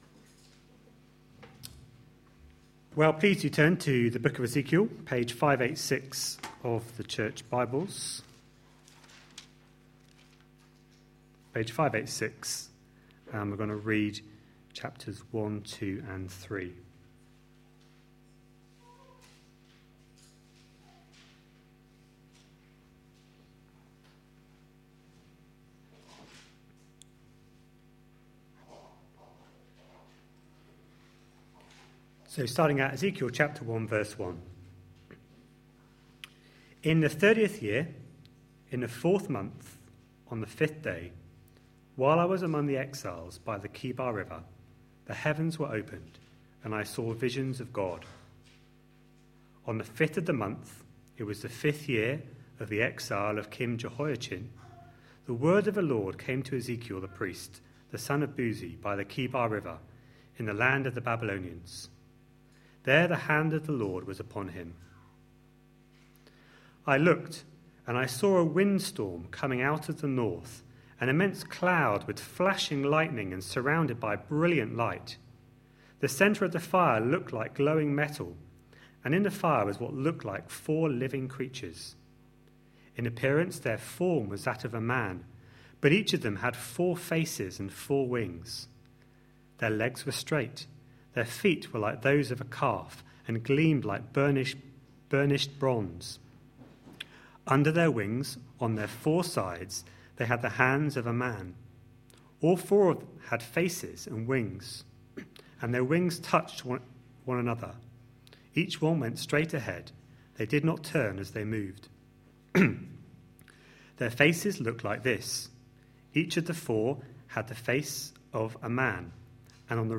A sermon preached on 1st June, 2014, as part of our Ezekiel series.